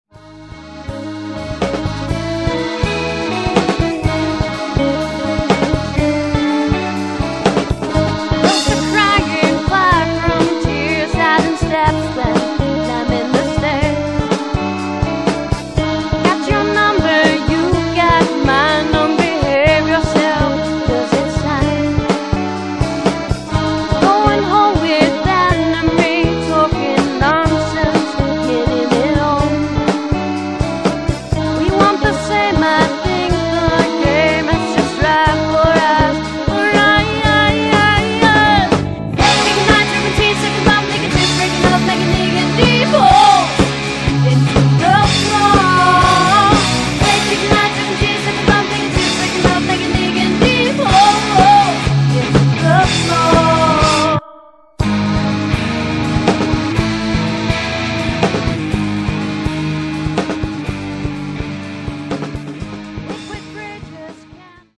eine Mischung aus Rock, Metal, Funk und starkem Gesang.
Piano / Backgroundgesang
Drums
Bass
Gitarre / Backgroundgesang